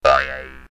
bounce3.ogg